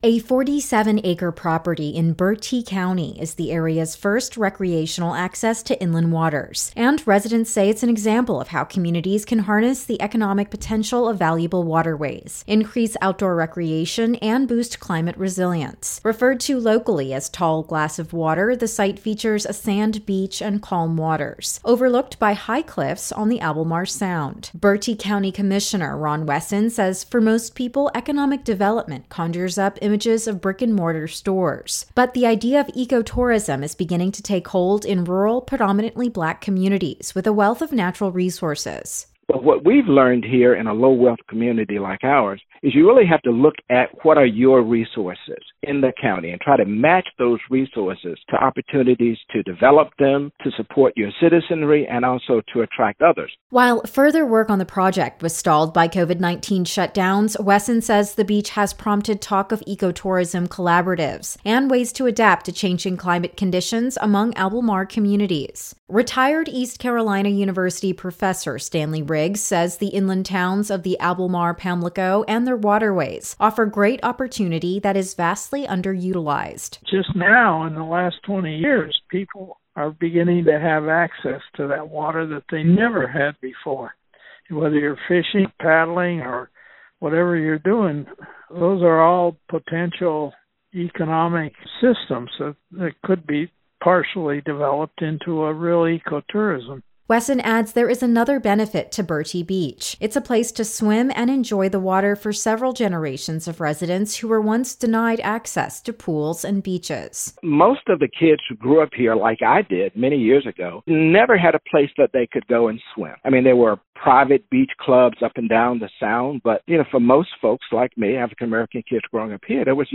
THE FOLLOWING RADIO REPORTS ARE DONE IN PARTNERSHIP WITH PUBLIC NEWS SERVICE.